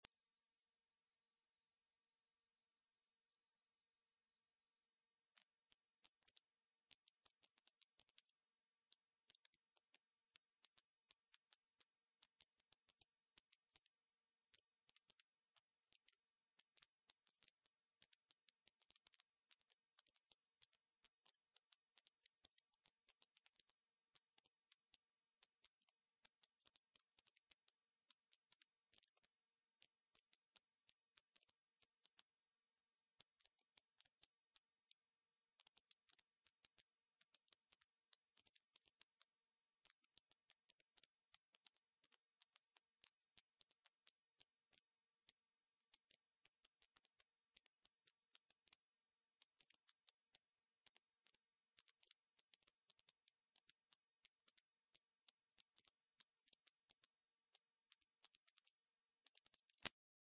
Feel the Power of 6666Hz Frequency!